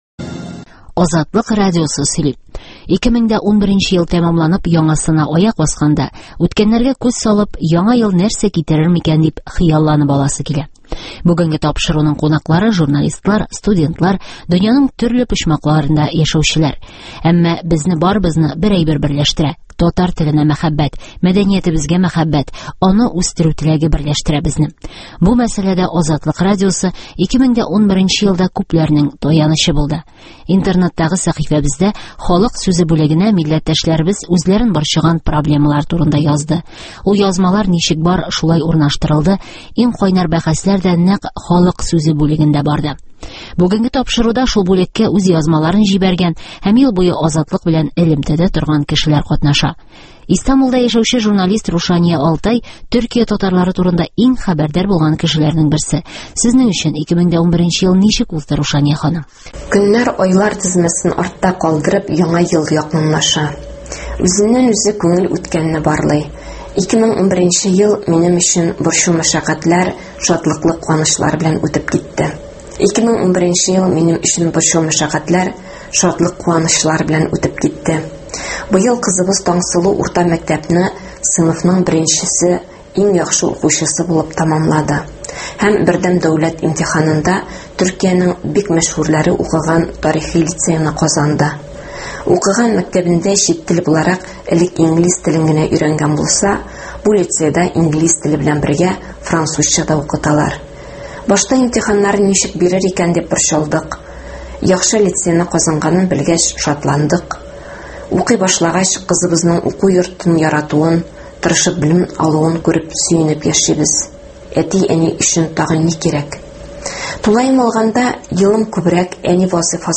Азатлыкның Яңа ел тапшыруы кунаклары – безнең "Халык сүзе" бүлегенә язмаларын җибәреп торган даими укучыларыбыз.